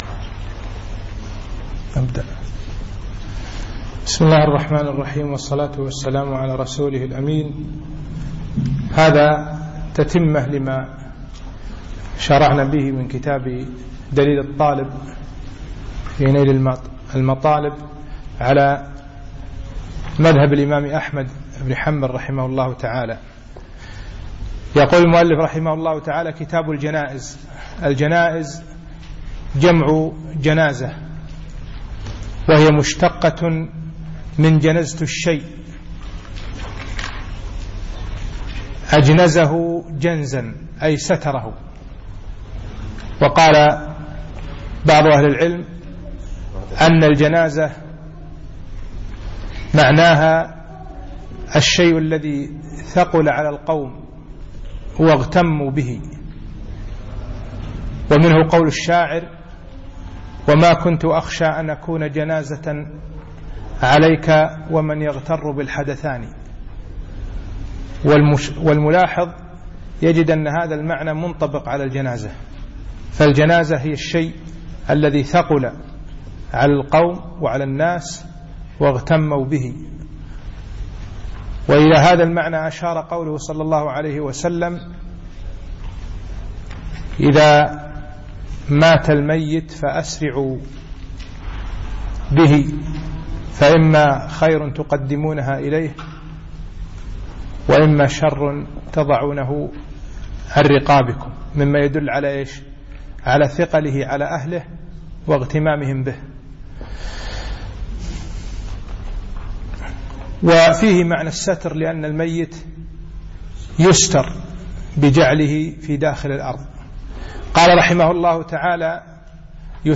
يوم الثلاثاء 15 شعبان 1436 الموافق 2 6 2015 بعد صلاة المغرب بمسجد سعد السلطان الفنطاس
الدرس الأول شرح كتابي الجنائز والصيام